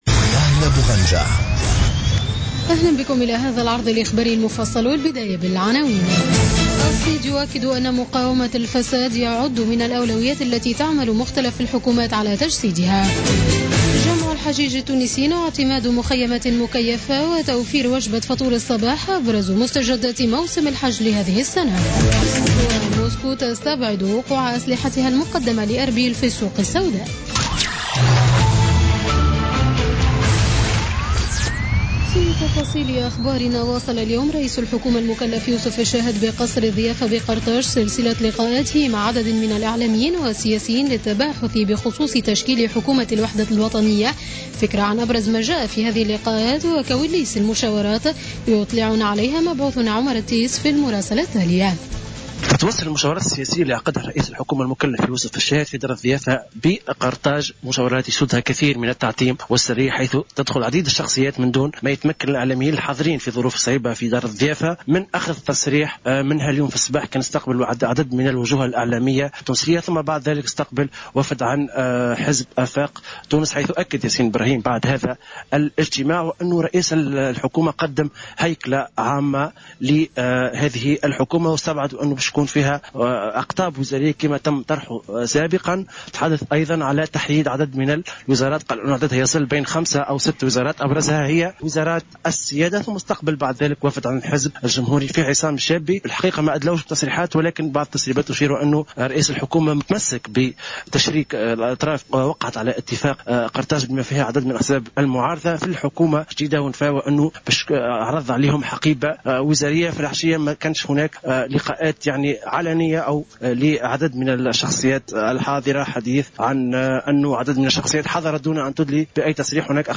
نشرة أخبار السابعة مساء ليوم الخميس 18 أوت 2016